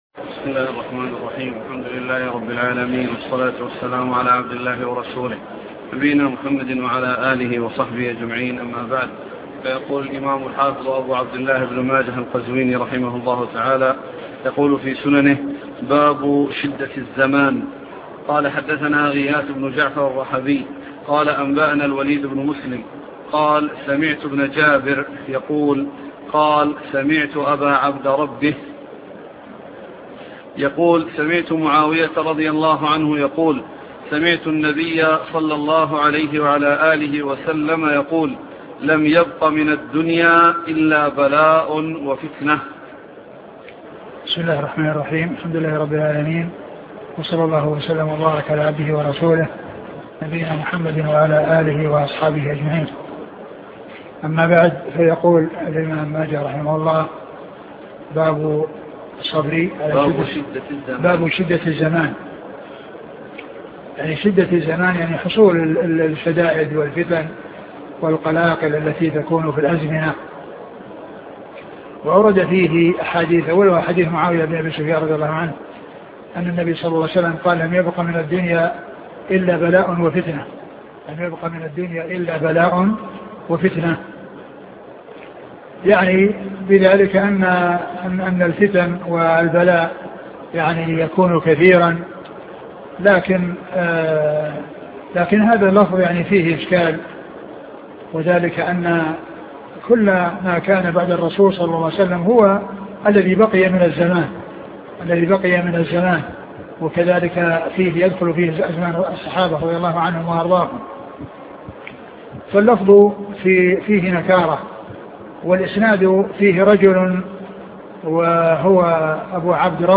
شرح سنن ابن ماجه الدرس عدد 292